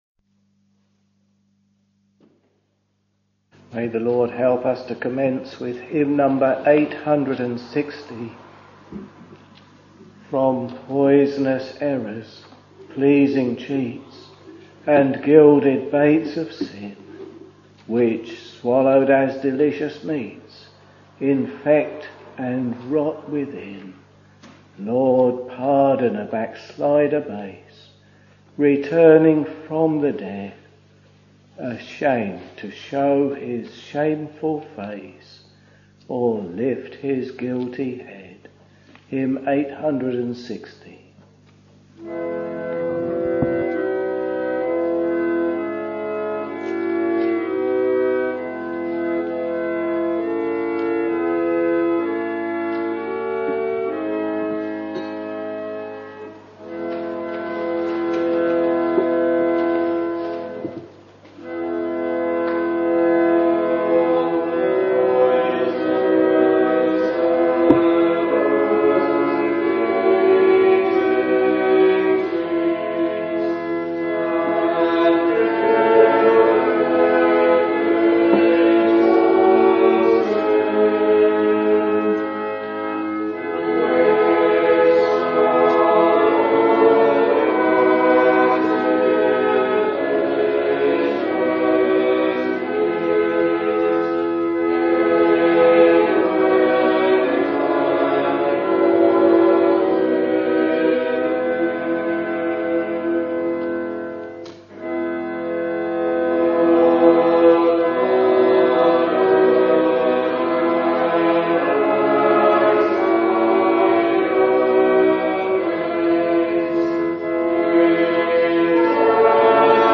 We are very pleased for you to listen to the live or archived services if you are not able to assemble for public worship in your local church or chapel.